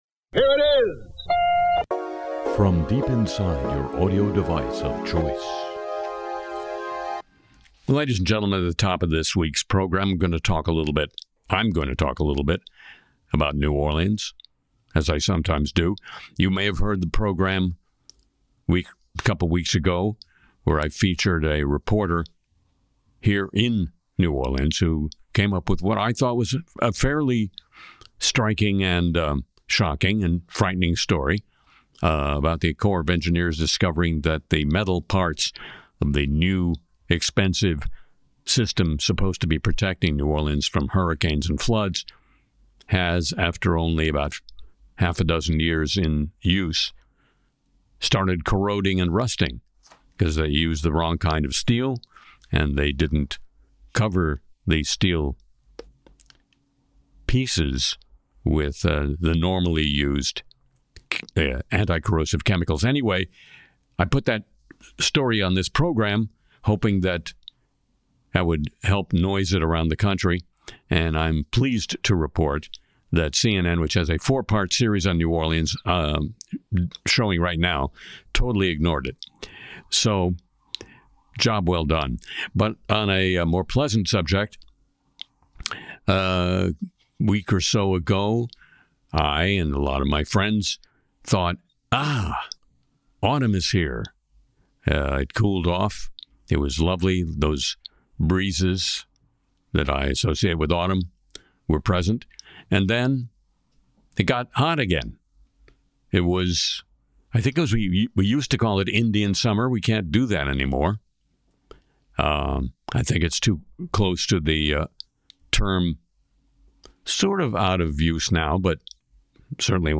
Harry Shearer’s Le Show (Oct 12 2025) features parody “Not the Joker,” AI flattery bots, Elon’s robot dreams, Autumn, and Trump satire.
Harry Shearer debuts “Not the Joker,” lampoons AI flattery bots, riffs on Elon’s robot hopes, savors Autumn in New Orleans, and skewers Trump’s latest antics.